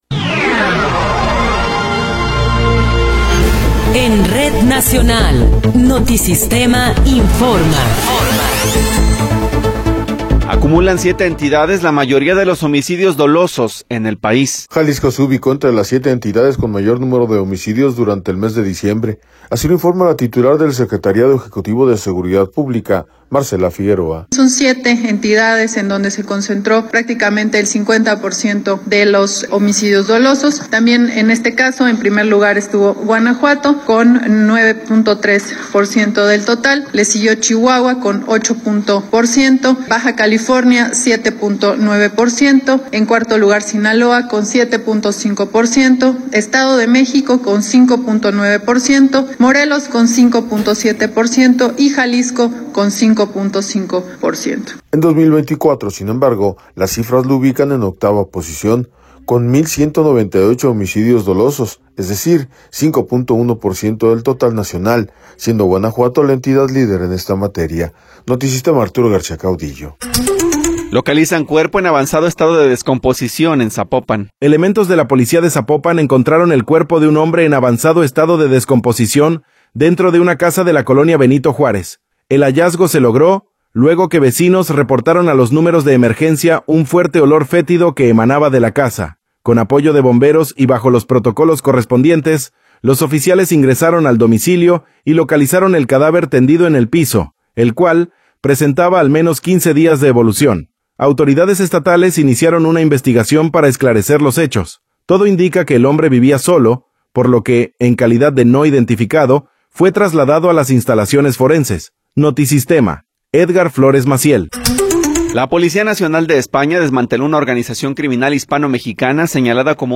Noticiero 10 hrs. – 8 de Enero de 2026
Resumen informativo Notisistema, la mejor y más completa información cada hora en la hora.